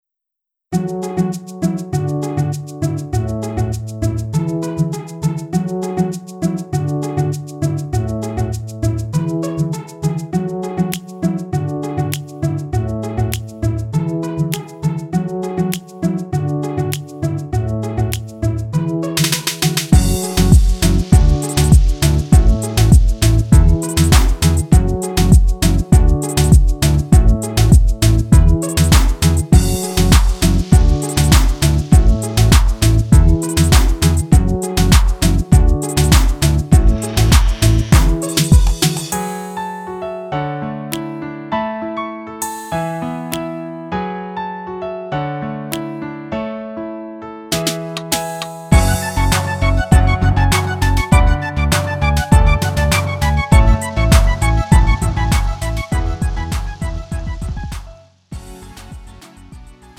음정 원키
장르 가요 구분 Lite MR
Lite MR은 저렴한 가격에 간단한 연습이나 취미용으로 활용할 수 있는 가벼운 반주입니다.